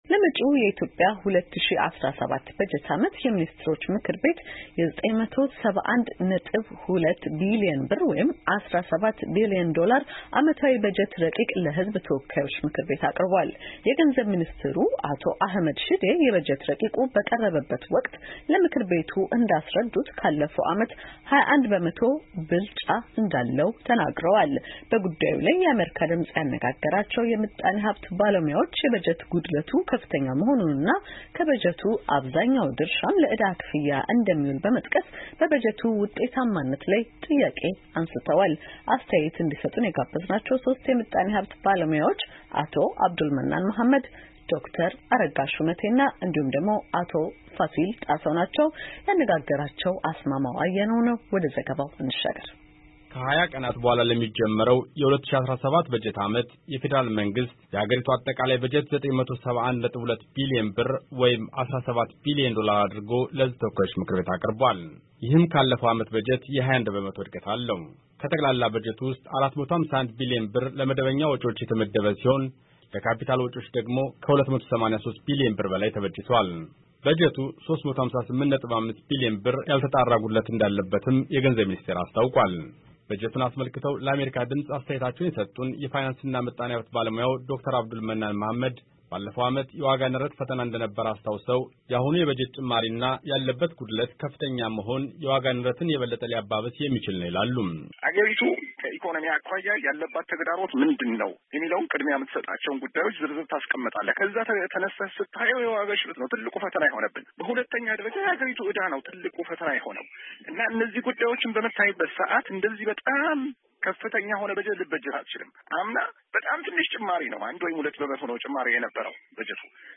በጉዳዩ ላይ የአሜሪካ ድምፅ ያነጋገራቸው የምጣኔ ሀብት ባለሞያዎች፣ የበጀት ጉድለቱ ከፍተኛ መኾኑንና ከበጀቱ ብዙ ድርሻ ለዕዳ ክፍያ እንደሚውል በመጥቀስ፣ በበጀቱ ውጤታማነት ላይ ጥያቄ አንሥተዋል።